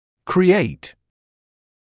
[krɪ’eɪt][кри’эйт]создавать, порождать, вызывать